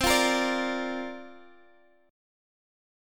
C9sus4 Chord (page 2)
Listen to C9sus4 strummed